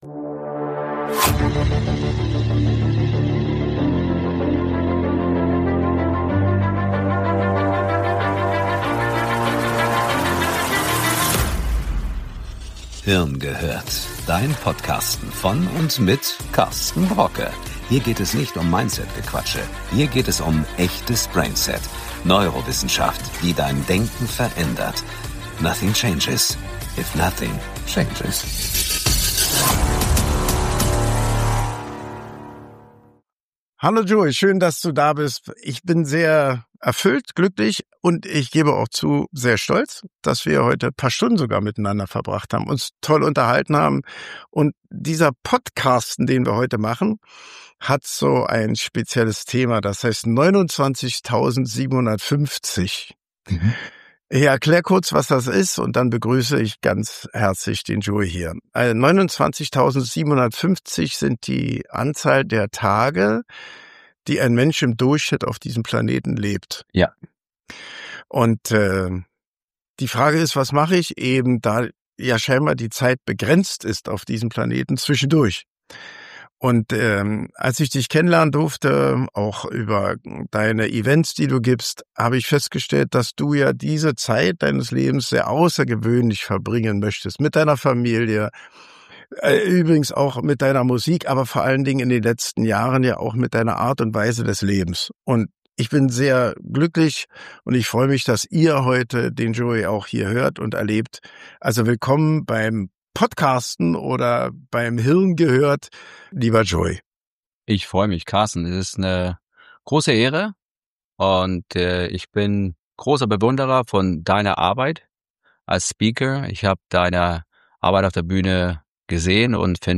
In dieser besonderen Folge von „Hirngehört“ treffen sich zwei Freunde.
Es geht um Familie, um Herkunft, um das, was uns antreibt und um die Kraft, immer wieder aufzustehen. Kein Filter, kein Show Talk, sondern zwei Menschen, die sich auf Augenhöhe begegnen und ehrlich über ihr Denken und Fühlen sprechen.